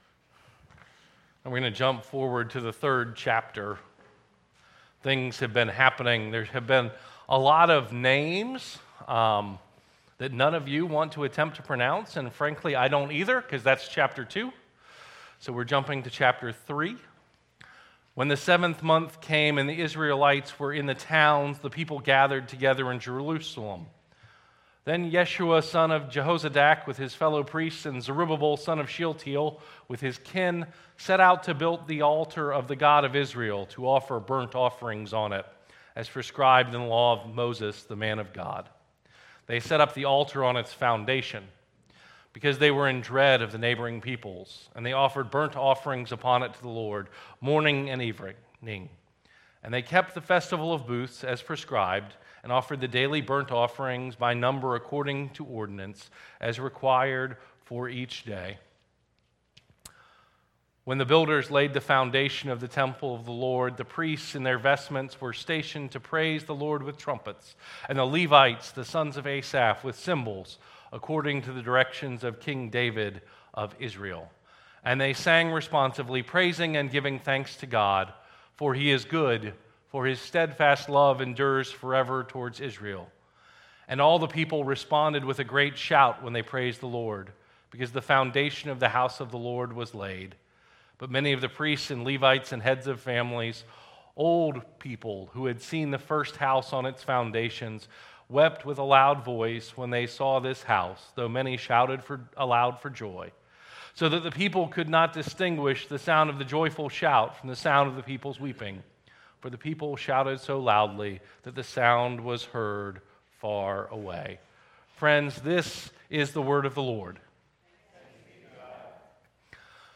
Worship 2019 December 8, 2019 - The Second Sunday of Advent - A Messiah from Where?